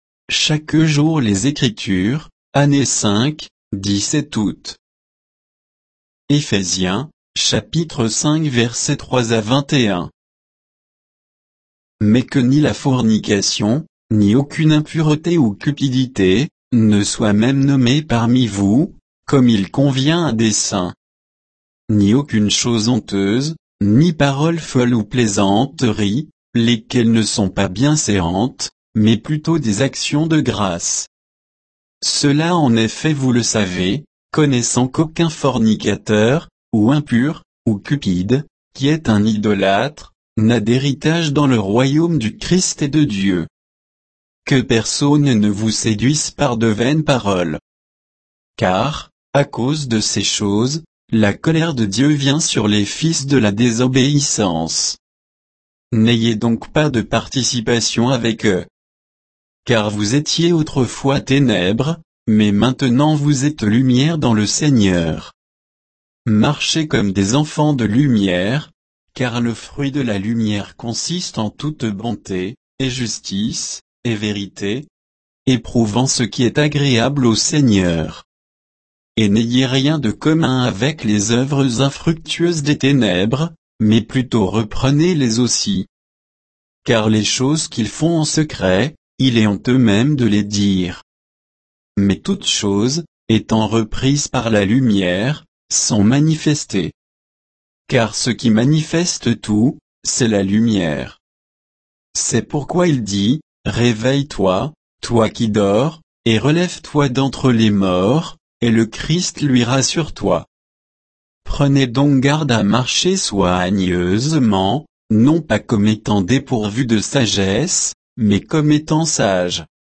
Méditation quoditienne de Chaque jour les Écritures sur Éphésiens 5